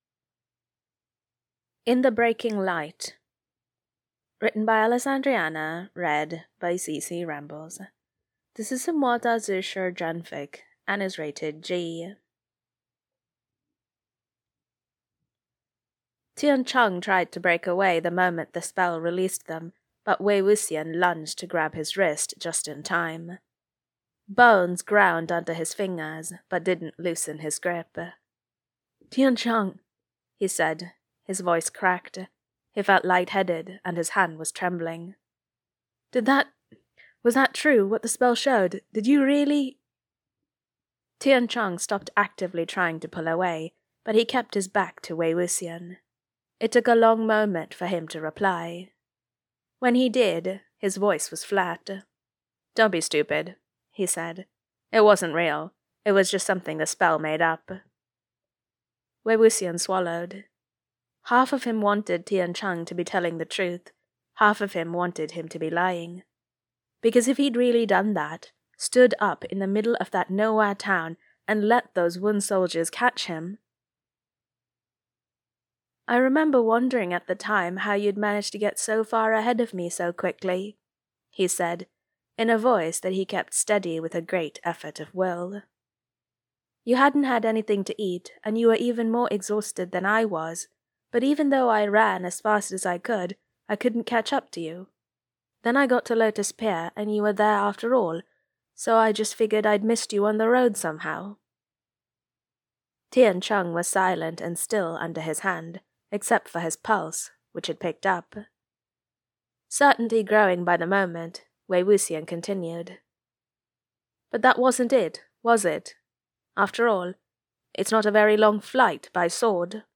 [Podfic] in the breaking light